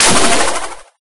Water3.ogg